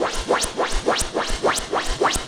Index of /musicradar/rhythmic-inspiration-samples/105bpm
RI_RhythNoise_105-01.wav